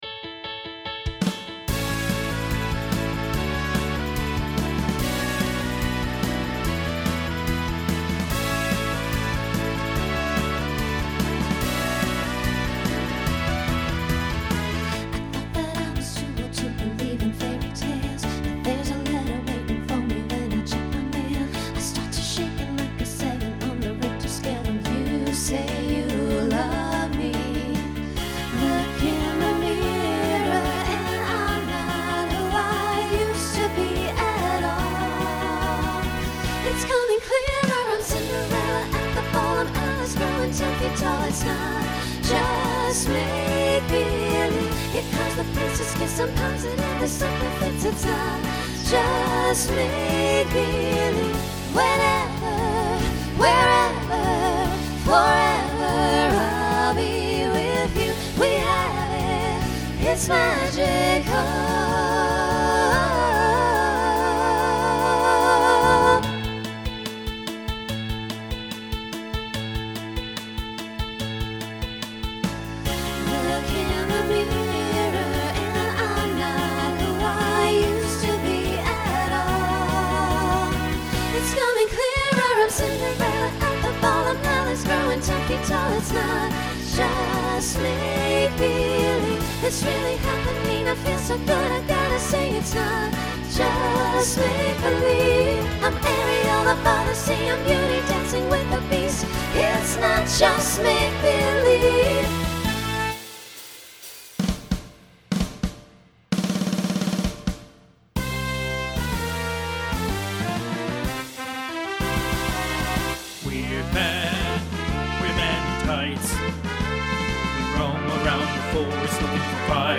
SSA